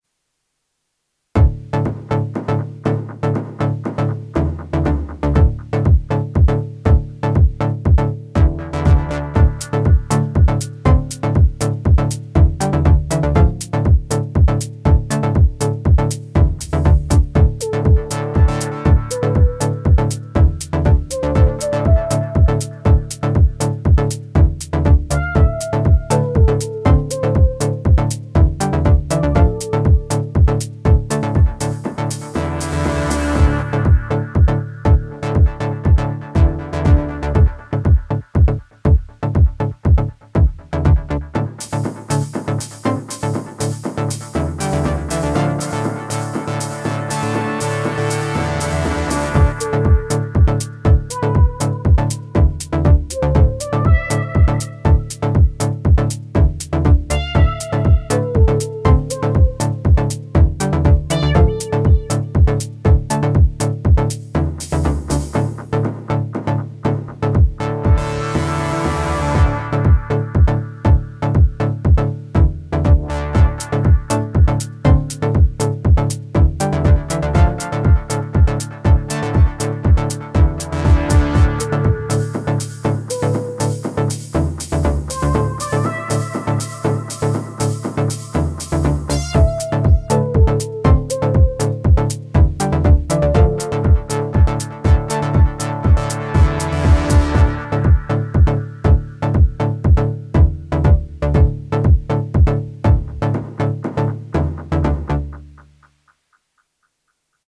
MPC Key 37. Finally got around trying those qlink macros in 3.0. Bit of a noodle to test.